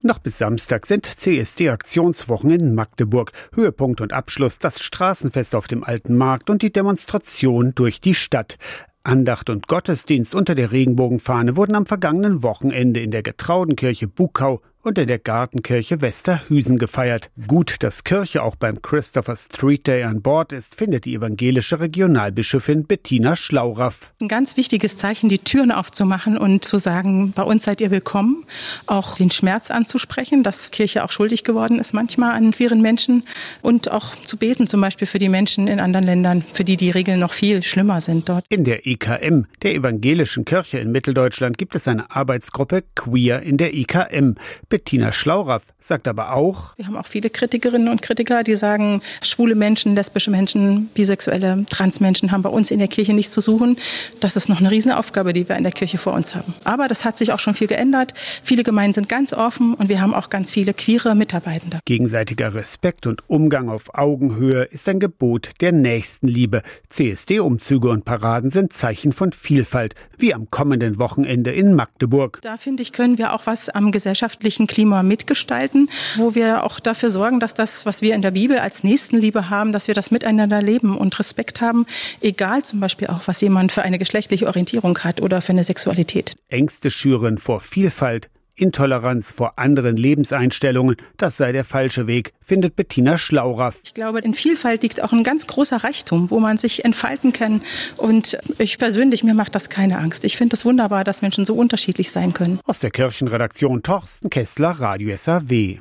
Gut, dass Kirche auch beim Christopher-Street-Day an Bord ist, findet die evangelische Regionalbischöfin Bettina Schlauraff.